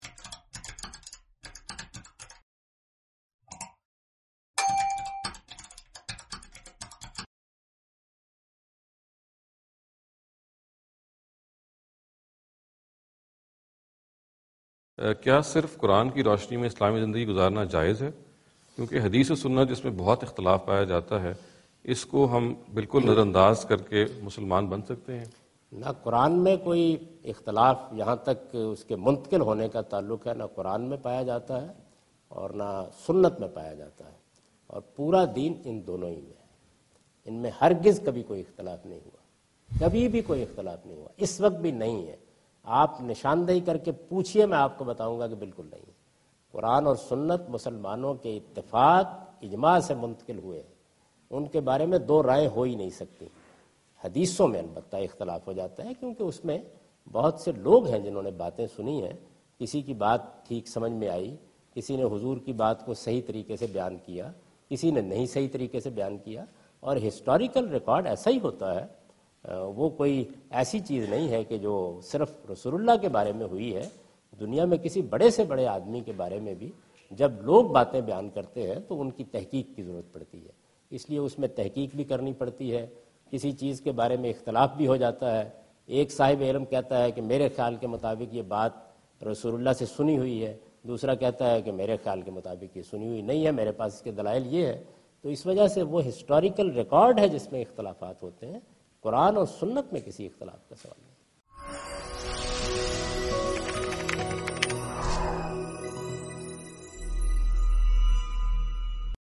Javed Ahmad Ghamidi answer the question about "Does Quran Suffice for Guidance?" during his visit to Georgetown (Washington, D.C. USA) May 2015.